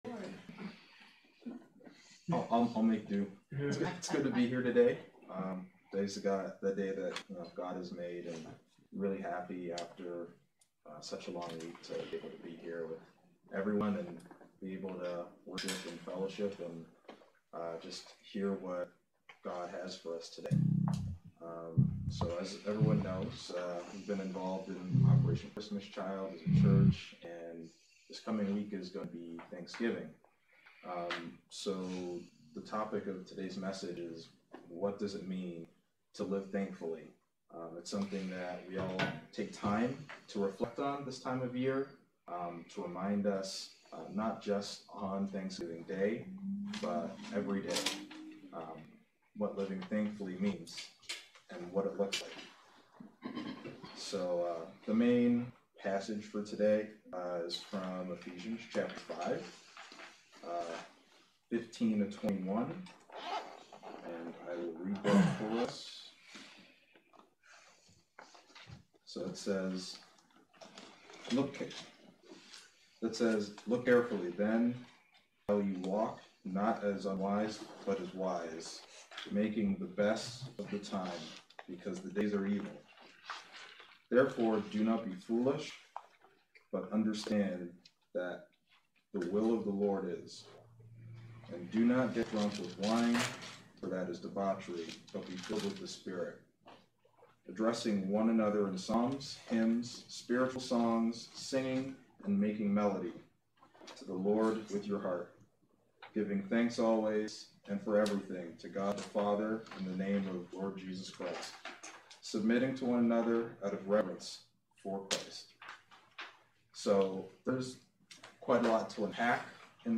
Misc Messages Service Type: Saturday Worship Service Speaker